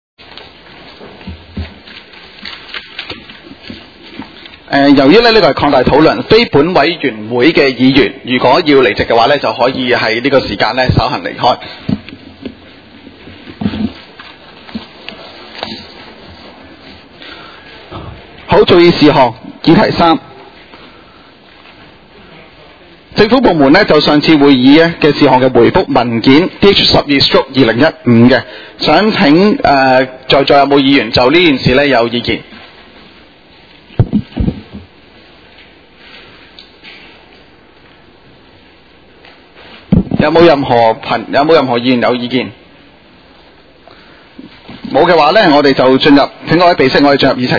委员会会议的录音记录